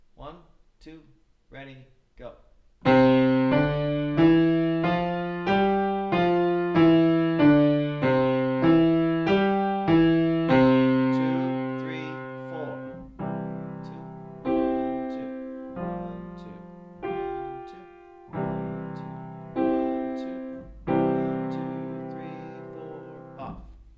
PIANO TIPS - WARM-UP EXERCISES
qn=96 (with dynamics), qn=100, qn=120)